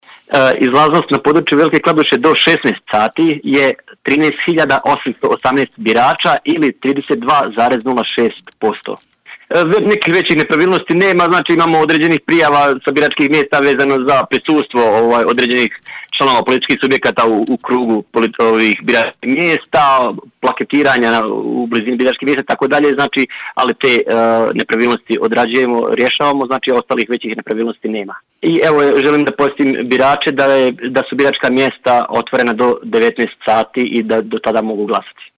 Svoje biračko pravo na Lokalnim izborima 2020. do 16.00h u Velikoj Kladuši ostvarilo je 13.818 birača, odnosno izlaznost birača u Velikoj Kladuši izražena procentima do 16:00h iznosi 32,06 posto, kaže u izjavi za Radio Velika Kladuša Edin Šabanagić, predsjednik Općinske izborne komisije.